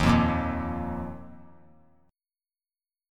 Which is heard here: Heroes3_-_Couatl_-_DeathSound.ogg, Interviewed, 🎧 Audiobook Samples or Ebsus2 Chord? Ebsus2 Chord